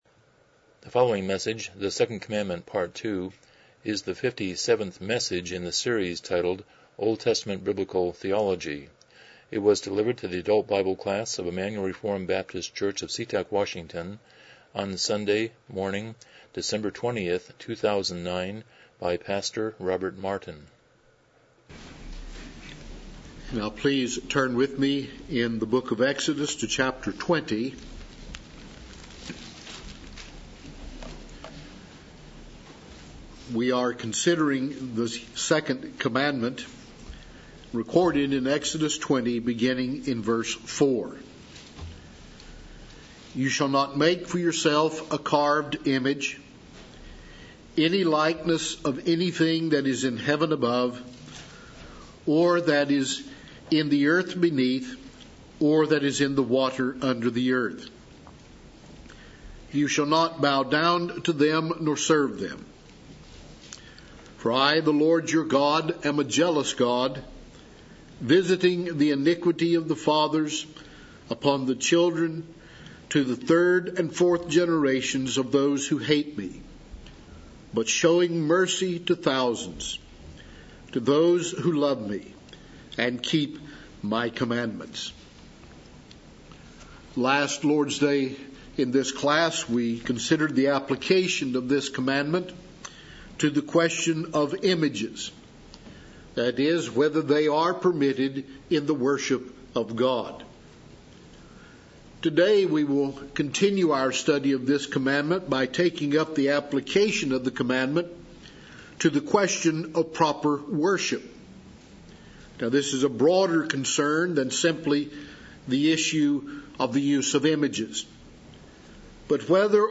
Series: Lessons in OT Biblical Theology Service Type: Sunday School « 82 Chapter 16.1